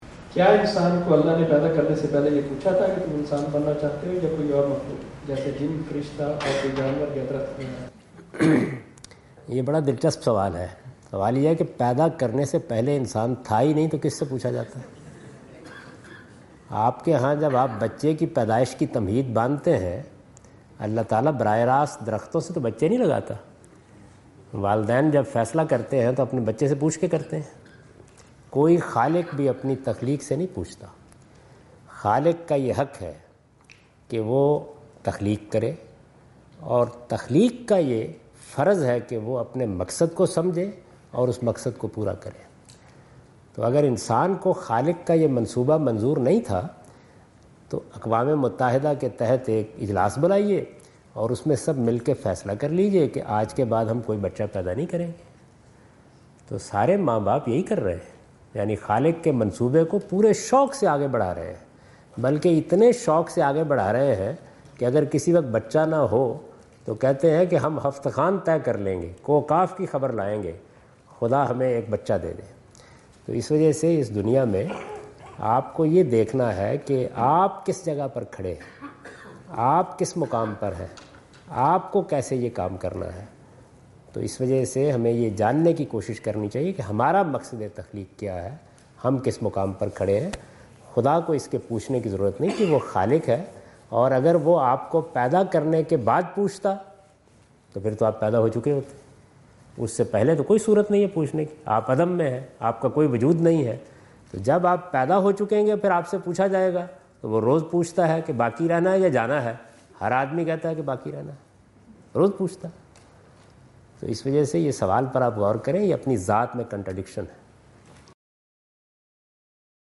Javed Ahmad Ghamidi answer the question about "Man's Choice in His Creation" in Macquarie Theatre, Macquarie University, Sydney Australia on 04th October 2015.
جاوید احمد غامدی اپنے دورہ آسٹریلیا کے دوران سڈنی میں میکوری یونیورسٹی میں "کیا اللہ نے انسان کو تخلیق کرنے سے پہلے اس کی مرضی دریافت کی؟" سے متعلق ایک سوال کا جواب دے رہے ہیں۔